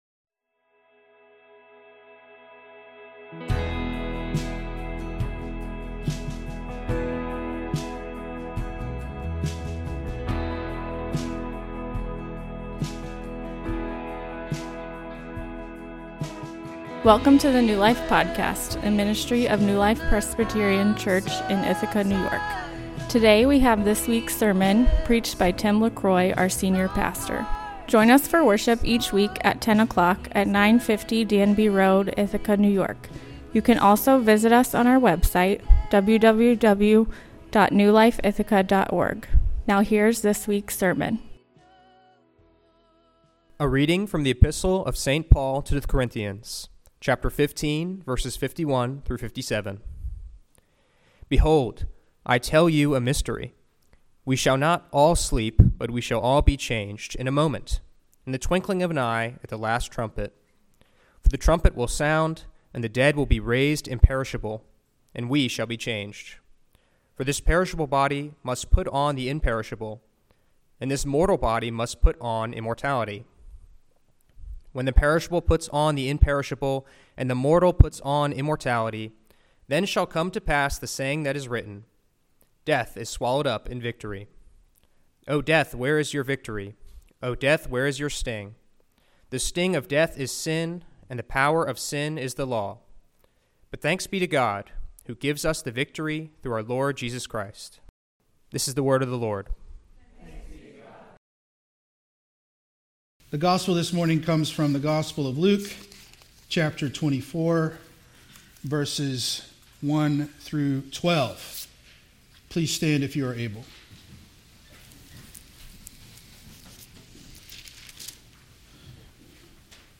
A sermon on the resurrection of Jesus from Luke 24:1-12